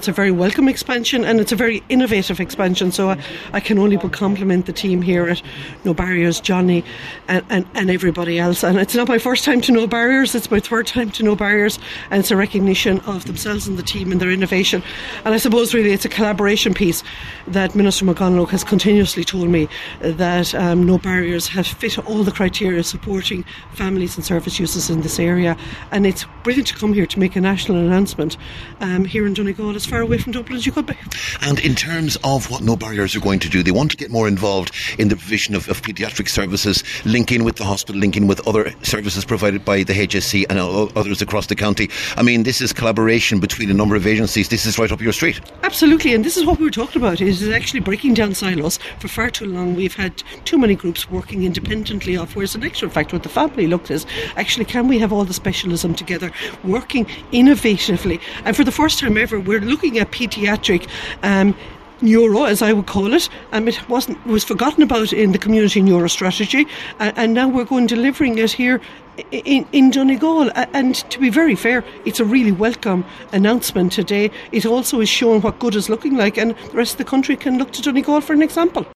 Minister Rabbitte says Donegal is a leading example to the rest of the country: